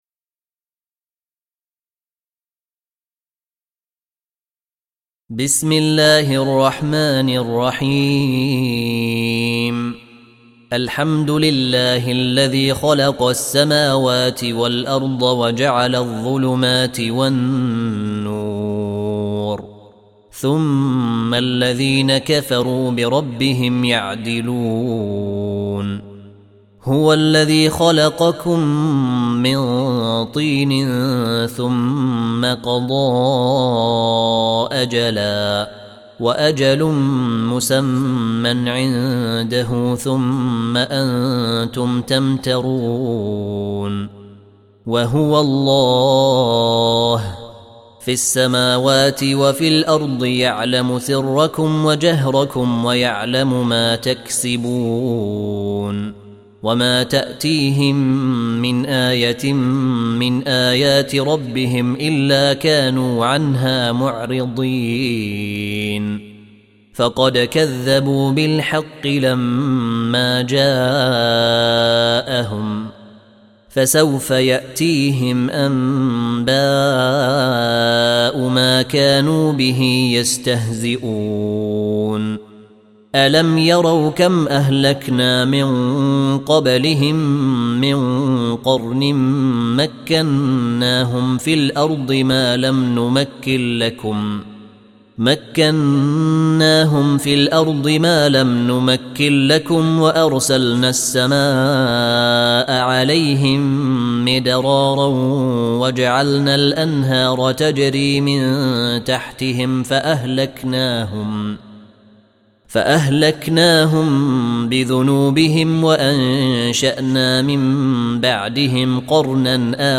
Audio Quran Tarteel Recitation
Surah Sequence تتابع السورة Download Surah حمّل السورة Reciting Murattalah Audio for 6. Surah Al-An'�m سورة الأنعام N.B *Surah Includes Al-Basmalah Reciters Sequents تتابع التلاوات Reciters Repeats تكرار التلاوات